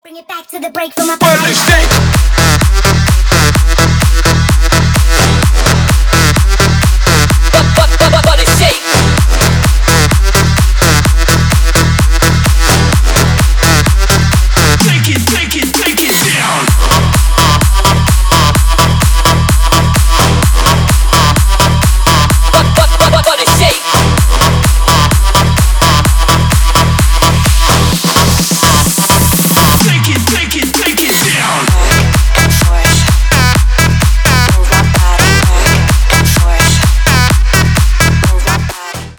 Электроника
громкие